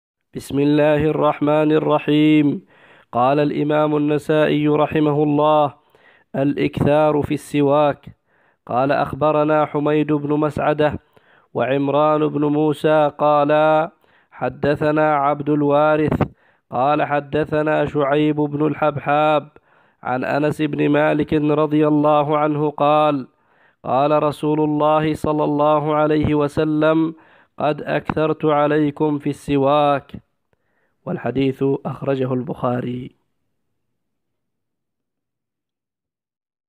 الكتب المسموعة